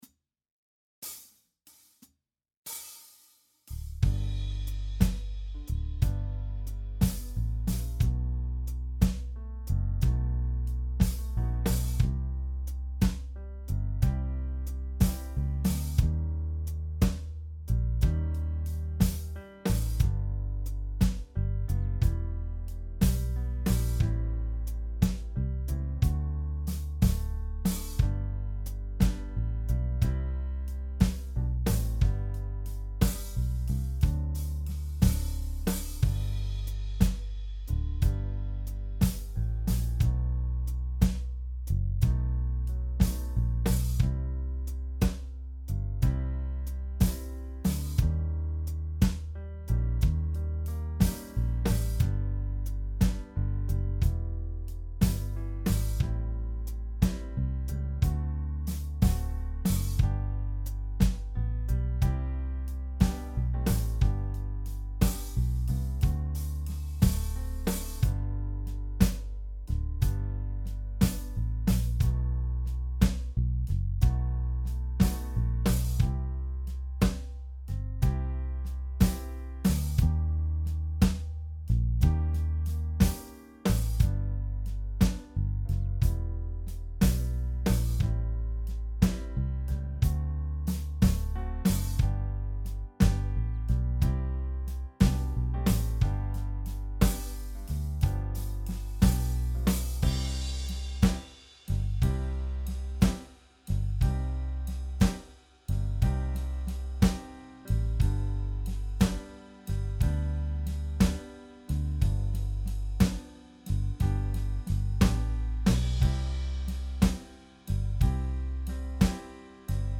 Jam Track